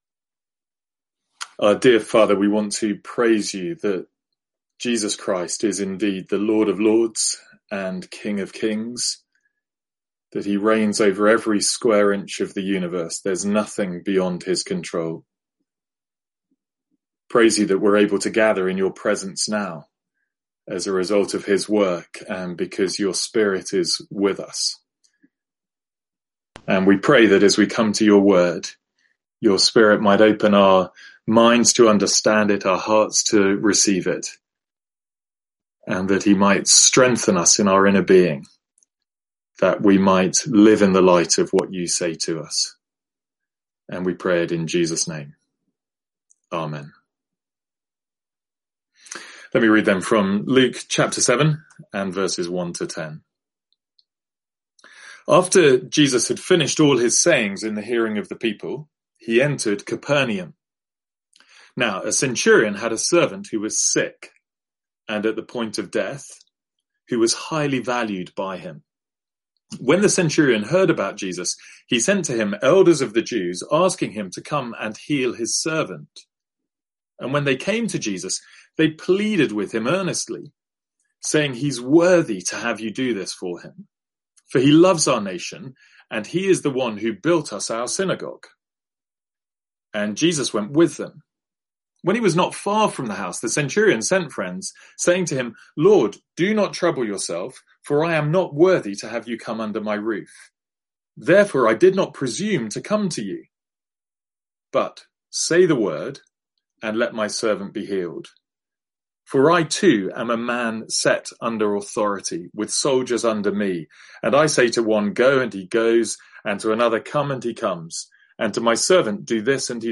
A one of sermon from Luke's Gospel.